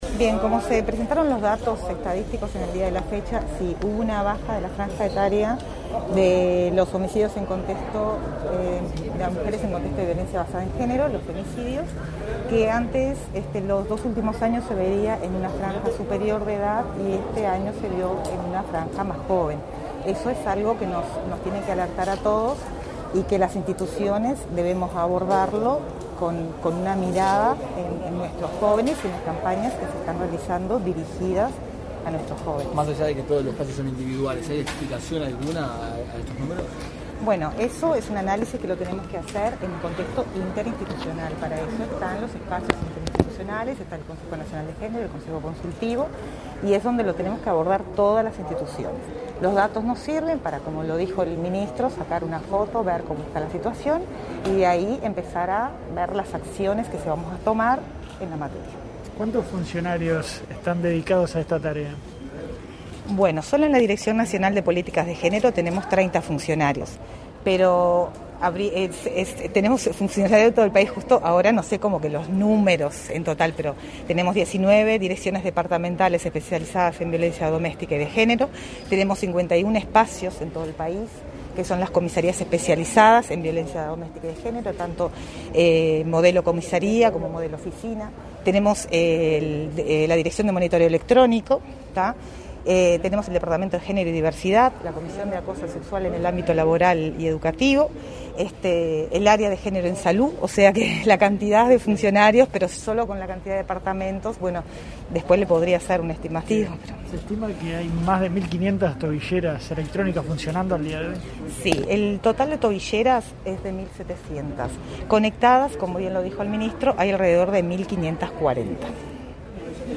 Declaraciones de prensa de la directora nacional de Políticas de Género, Angelina Ferreira
ferreira prensa.mp3